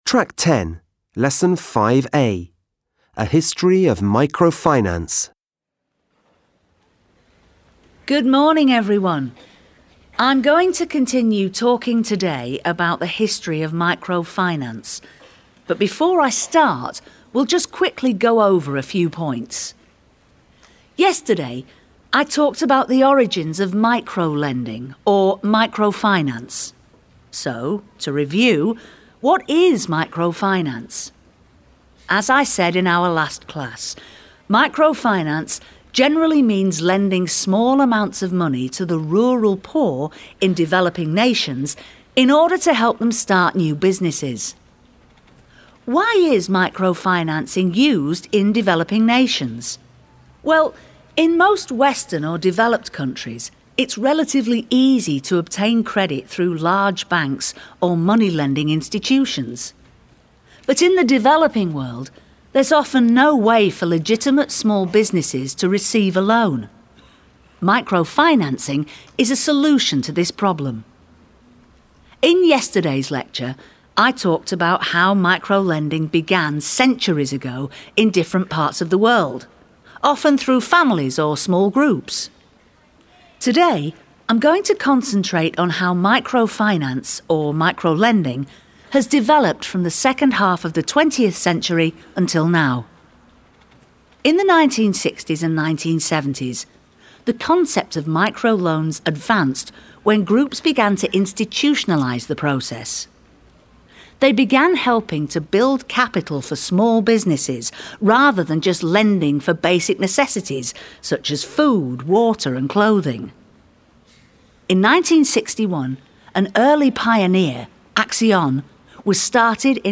5. Mokytojas pakviečia mokinius pasiklausyti visos paskaitos apie mikrofinansavimą. 5.